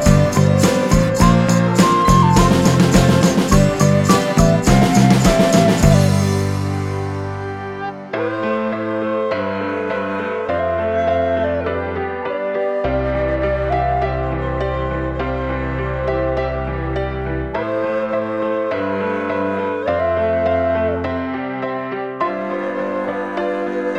No Backing Vocals Rock 3:19 Buy £1.50